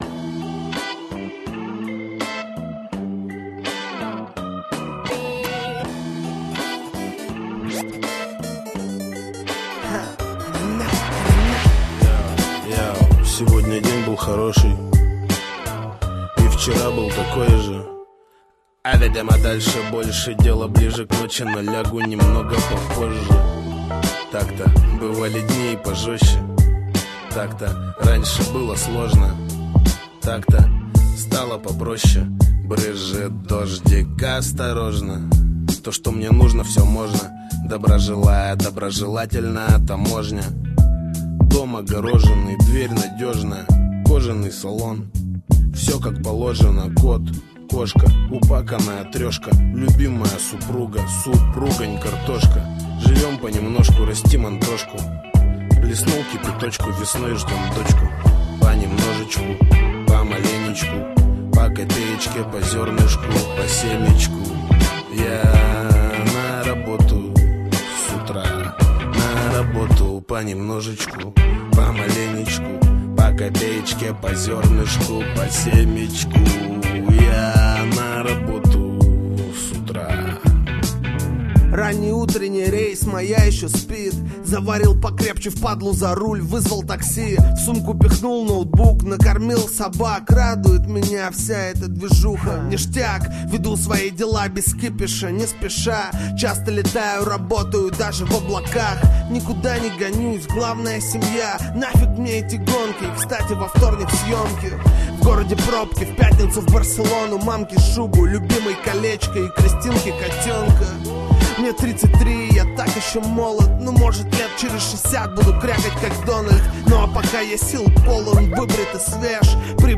Русский рэп скачать бесплатно